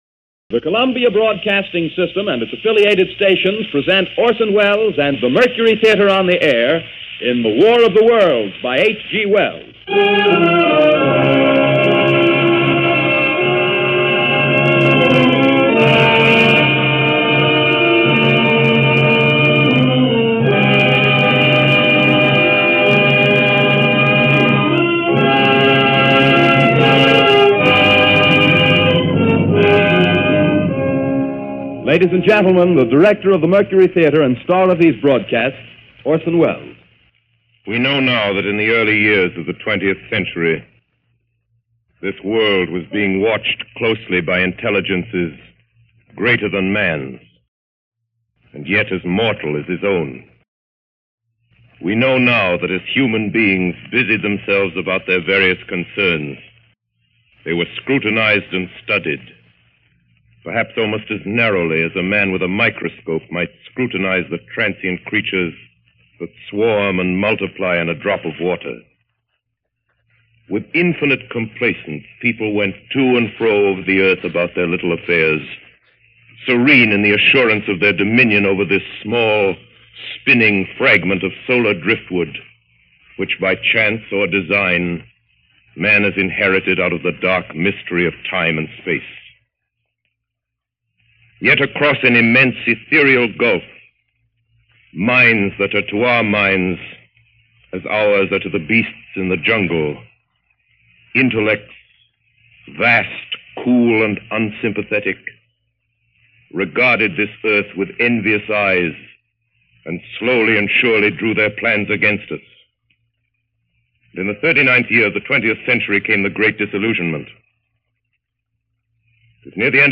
Recording of the WKBW version of War Of The Worlds, October of 1968.